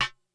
Rimshot.wav